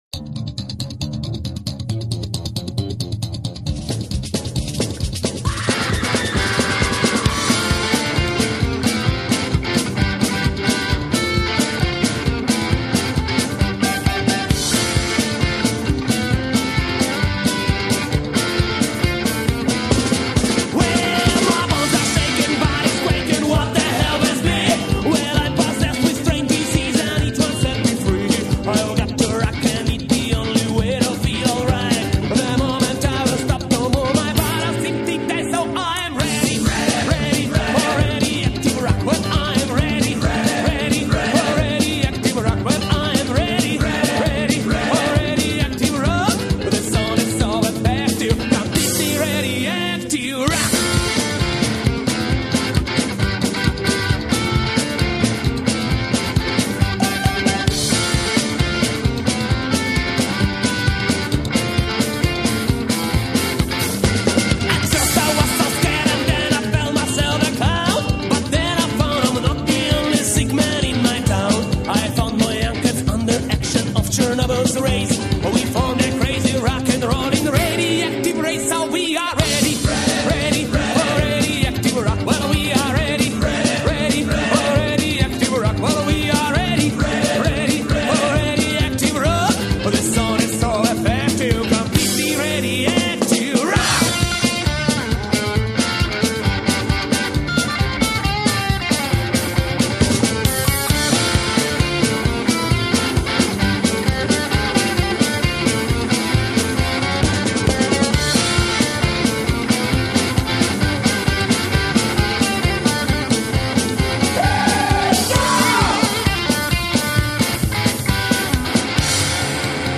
рок-энд-рол